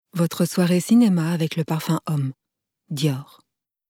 Voix off
5 - 53 ans - Mezzo-soprano